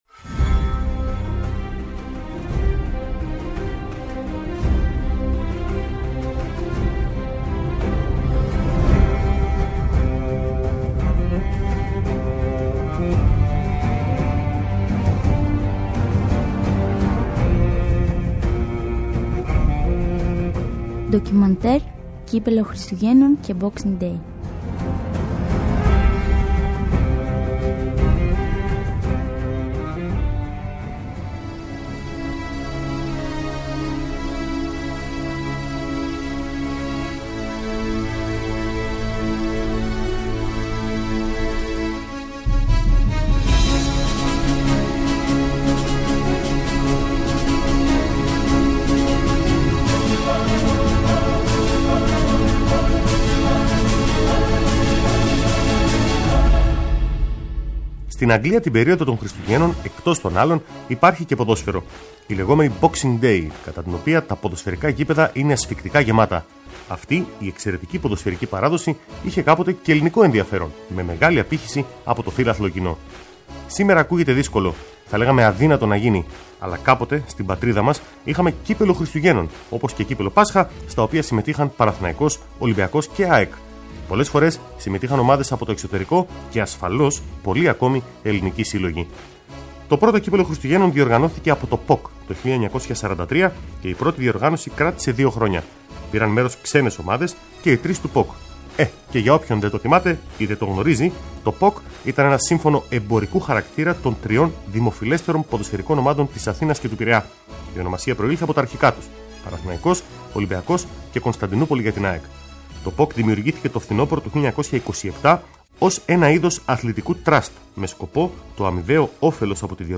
Μια εκπομπή με ιστορικές αναφορές σε πρόσωπα και γεγονότα που σημάδεψαν τον αθλητισμό εντός κι εκτός Ελλάδας. Έρευνα και σπάνια ηχητικά ντοκουμέντα, σε μια σειρά επεισοδίων από την ΕΡΑσπορ